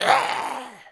minf_attack_v.wav